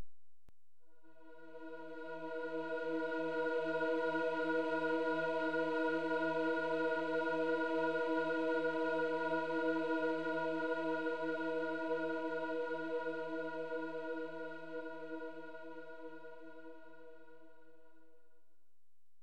E mit 324 Hz und 432 Hz Kammerton,
mit Schwebung im Theta Bereich,
inklusive  Quintenklang und Bass eine Oktave tiefer.
E324Hzviolett.wav